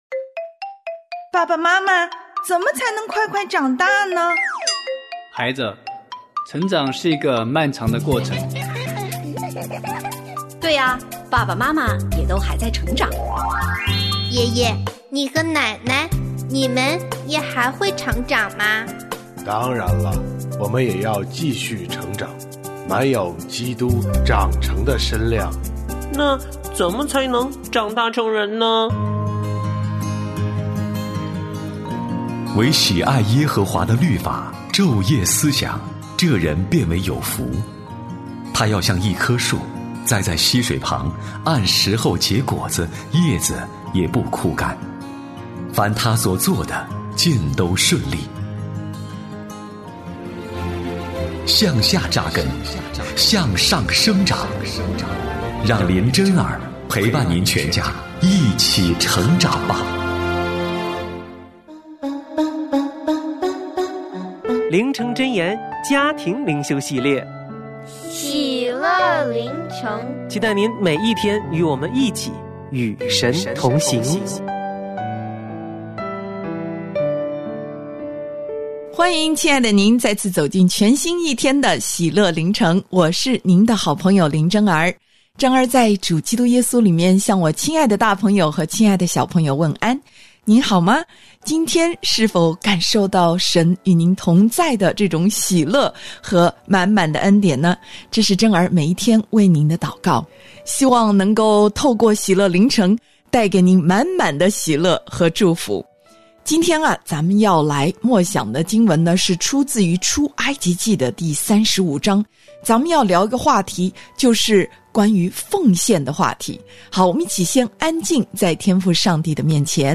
我家剧场：圣经广播剧（159）上帝管教骄傲的希西家王；罪大恶极的玛拿西王